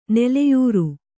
♪ neleyūru